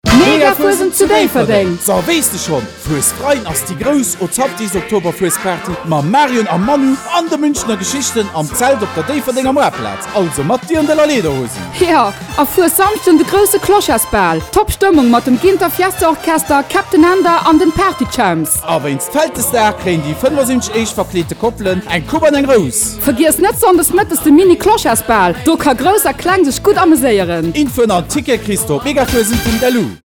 Radiospot Megafuesend 2015